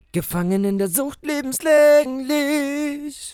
Knackser entfernen aus Audiotrack (Vocals)
Nabend, irgendwie habe ich mir gerade bei einer Render in Place Orgie einen hässlichen kurzen aber deutlichen Knackser auf einem Vocaltrack eingefangen...